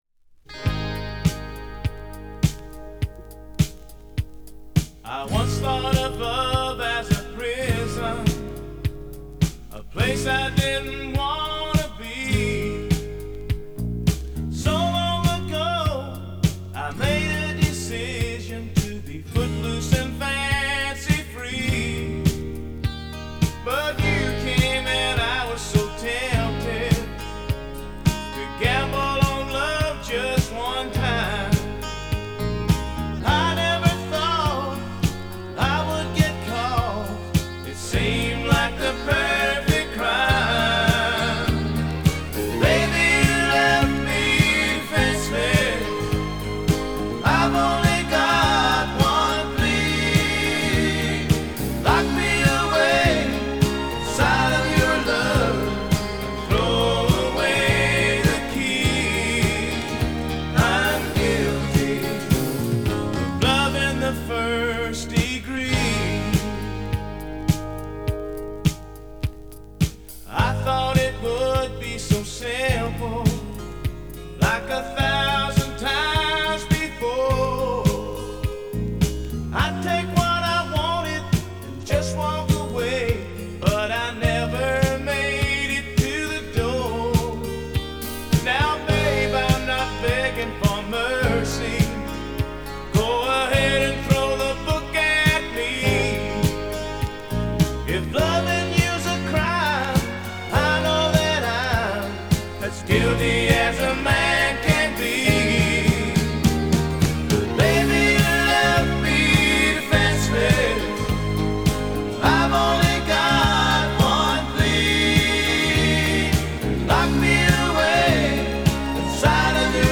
американская кантри-рок-группа.